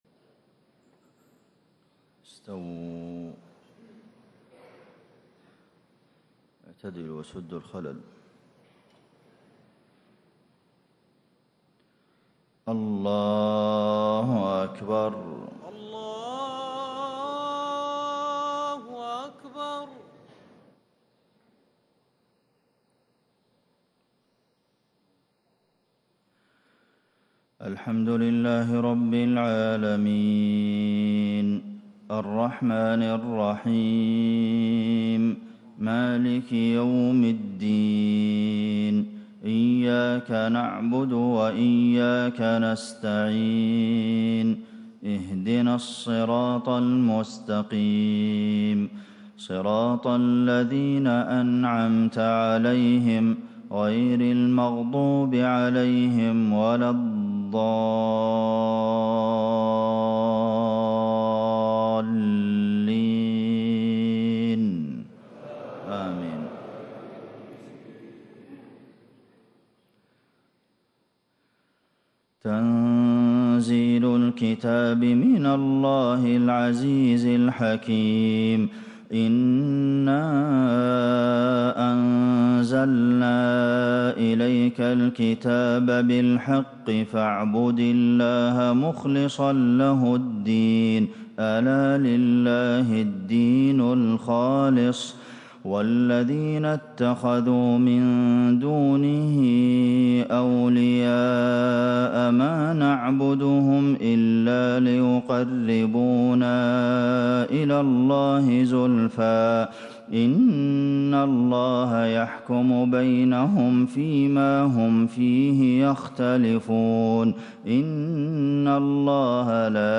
صلاة الفجر 7 ذو القعدة 1437هـ فواتح سورة الزمر1-16 > 1437 🕌 > الفروض - تلاوات الحرمين